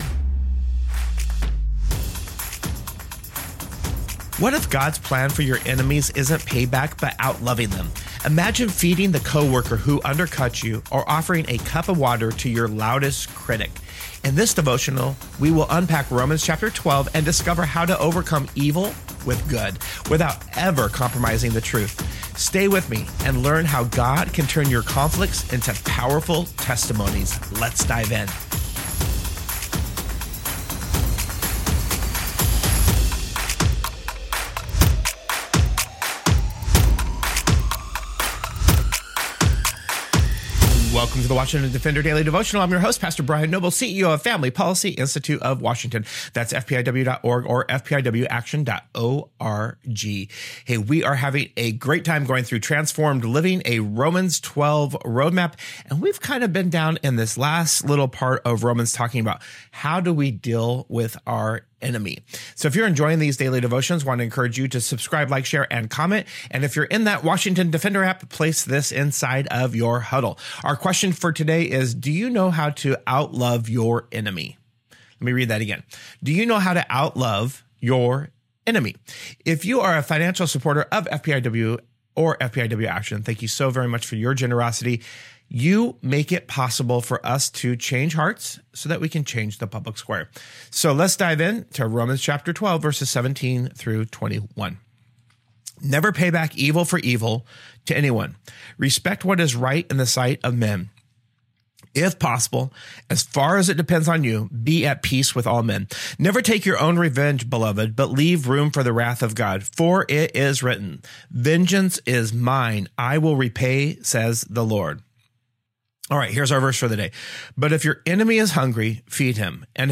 In this devotional, we unpack Romans 12 and discover how to overcome evil with good without ever compromising truth.